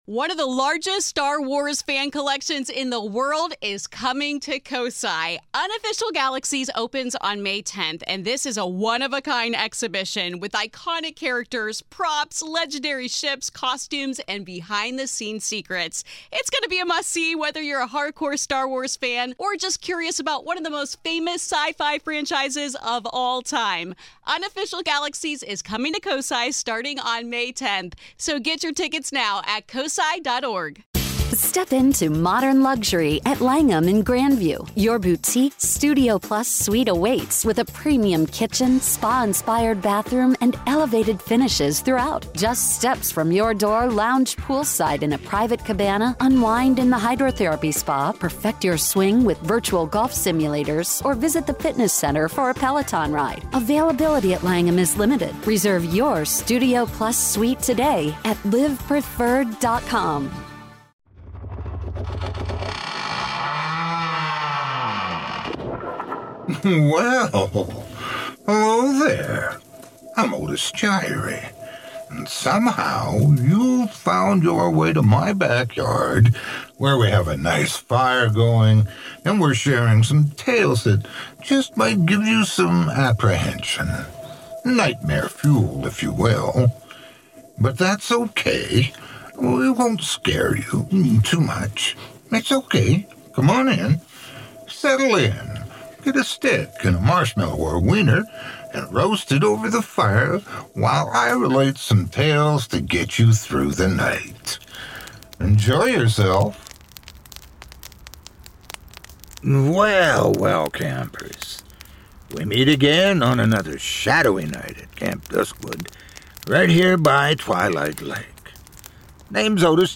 A Horror Anthology and Scary Stories Podcast